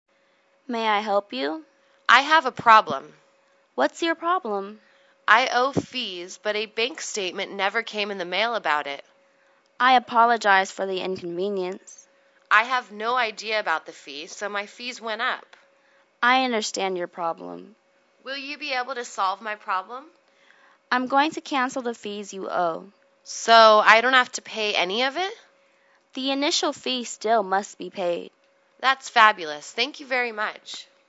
银行英语对话-Bank Statement(3) 听力文件下载—在线英语听力室